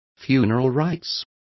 Also find out how exequias is pronounced correctly.